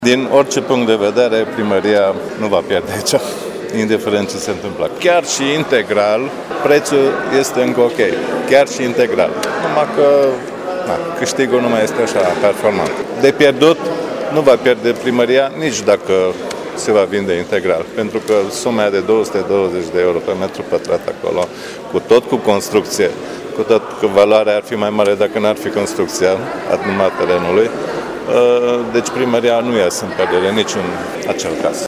Potrivit consilierului local Ioan Ștefan Szatmari, primăria face o afacere bună cu imobilul pentru care plătește 120.000 de euro, dar nu ar ieși în pierdere nici dacă ar cumpăra și clădirile de 60.000 de euro: